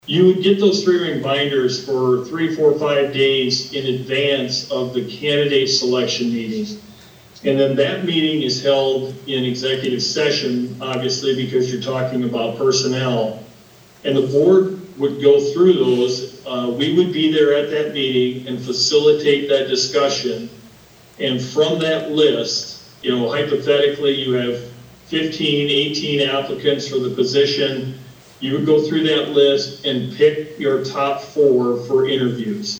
appearing via Zoom gave the Board a 45 minute presentation of their services